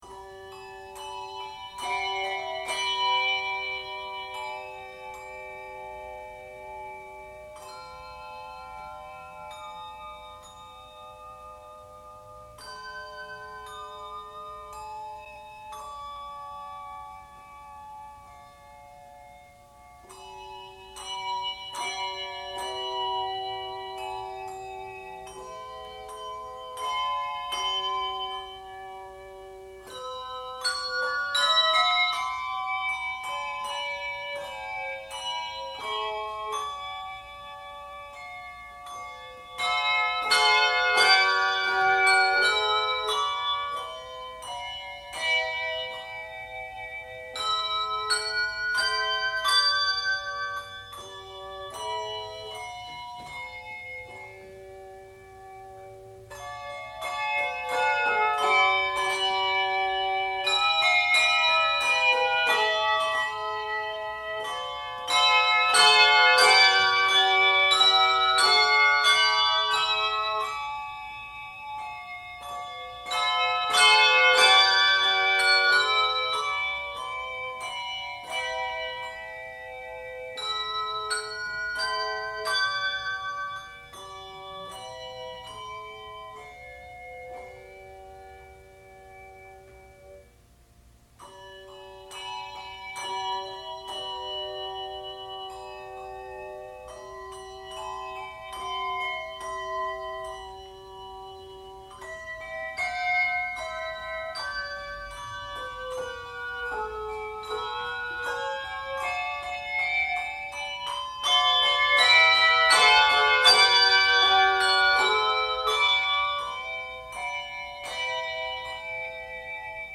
for the 2 octave choir
colorful harmonies